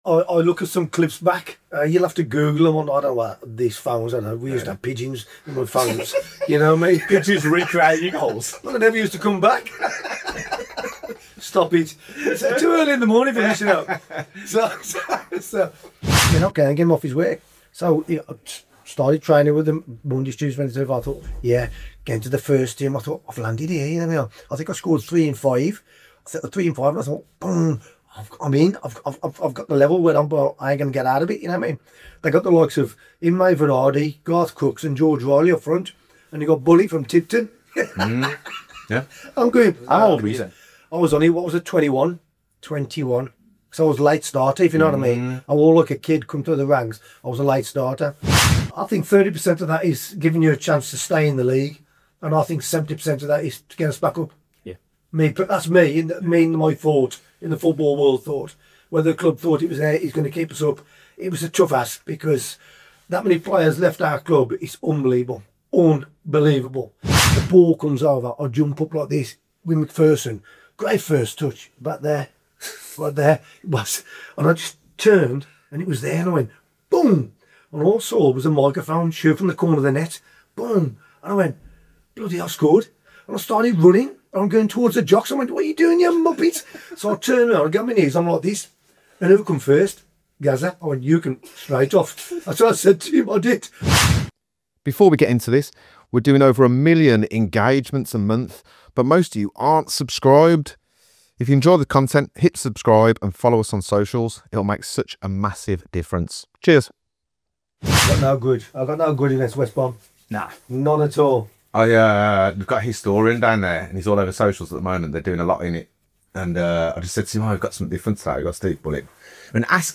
Steve Bull — a true Wolverhampton Wanderers legend — joins us for a raw, no-filter conversation about what football was really like before the modern era softened the game. From bone-crunching tackles to relentless aerial battles, Bully breaks down the physical and mental toughness needed to survive in one of football’s most unforgiving eras.
🎙 Filmed at Birmingham City Football Club 📍 From the Made in Brum Podcast ⸻ 👉 Like, comment & subscribe for more unfiltered stories from legends in football, music, and Birmingham culture.